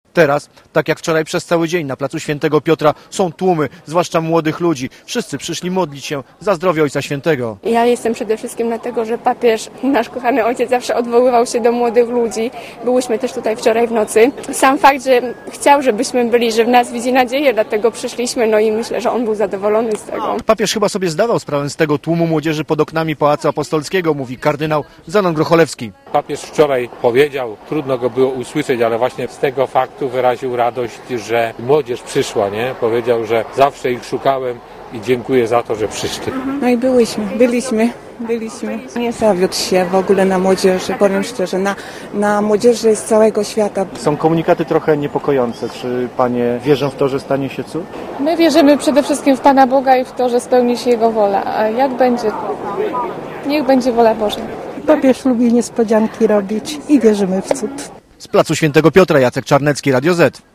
Dziesiątki tysięcy ludzi czuwa na placu świętego Piotra w Rzymie.
watykan_-_sobota_noc.mp3